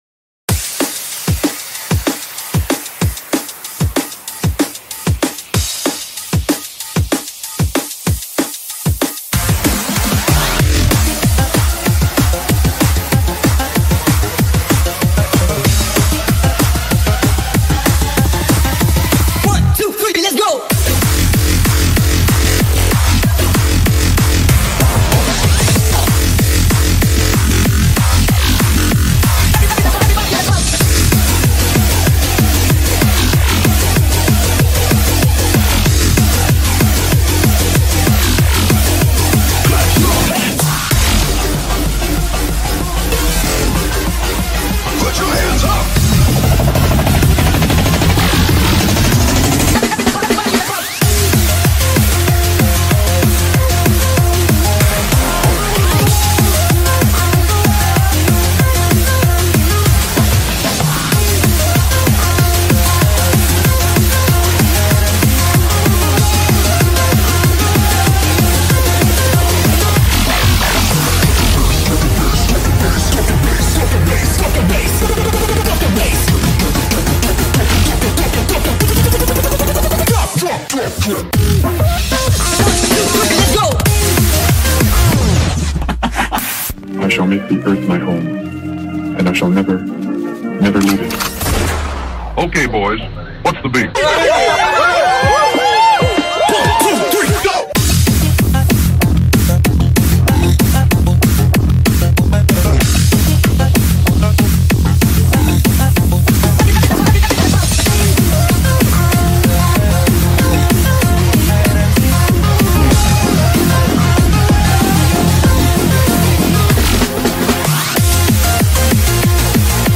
BPM190-380
Audio QualityPerfect (Low Quality)